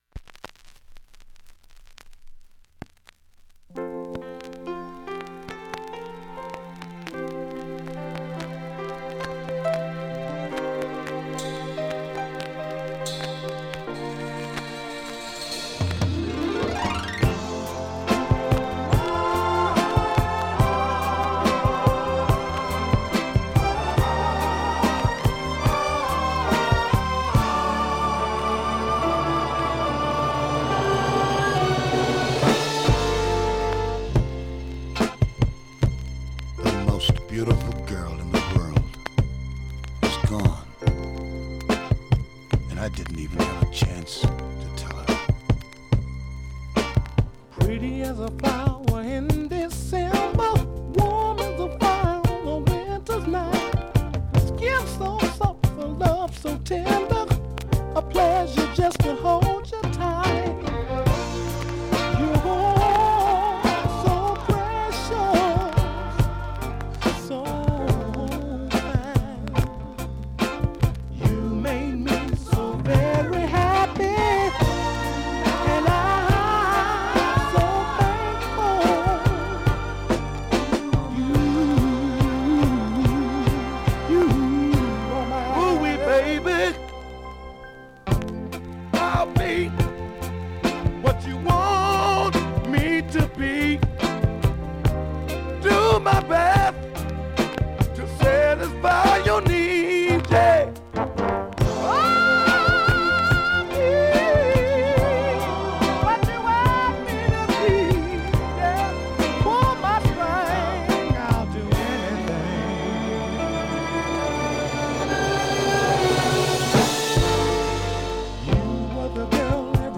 ◆盤質Ａ面/VG+ 始めに１０回,途中に５回２箇所プツ出ます。